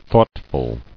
[thought·ful]